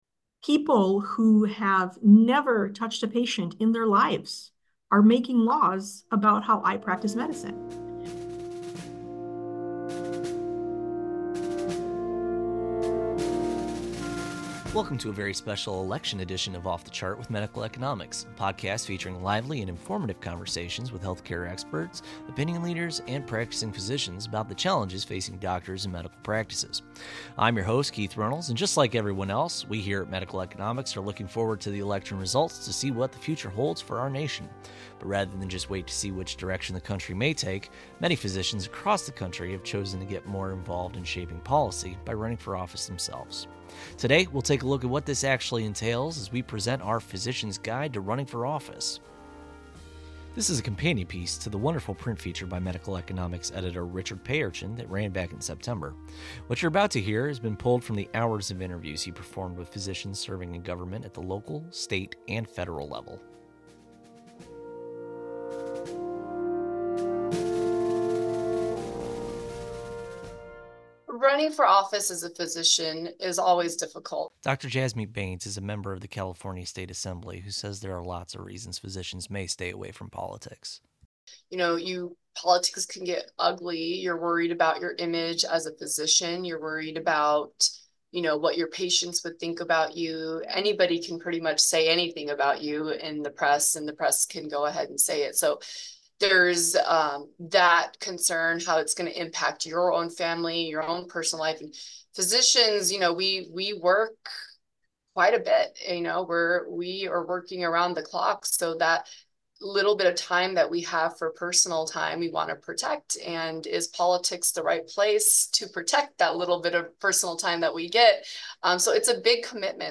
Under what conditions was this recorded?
This episode has been pulled from the hours of interviews he performed with Physicians serving in government at the local, state, and federal level.